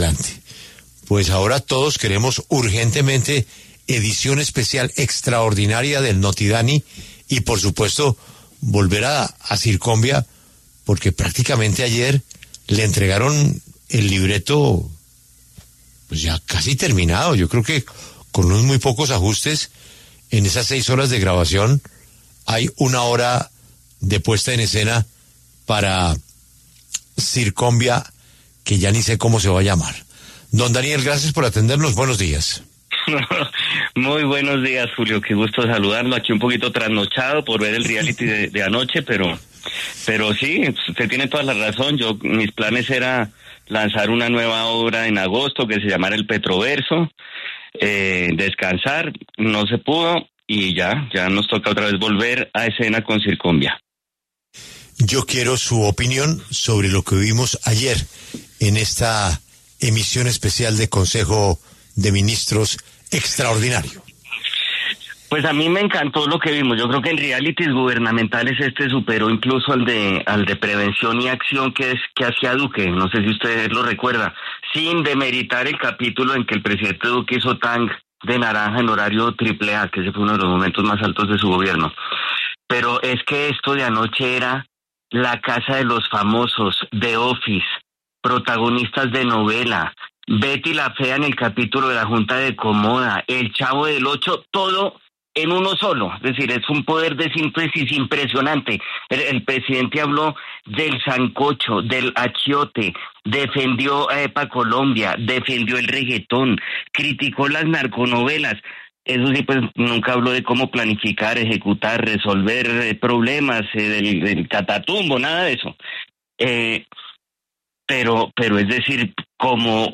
El periodista Daniel Samper Ospina pasó por los micrófonos de La W y reveló que aunque tenía planeado hacer un nuevo show de su obra ‘Circombia’, el consejo de ministros del Gobierno Petro, transmitido en televisión nacional, le dio mucho contenido para lanzar próximamente una escena nueva.
“Lo regañó el presidente por llegar tarde, esto es el colmo, después lo va a regañar por volarse a Panamá, o algo así”, dijo Samper en medio de risas.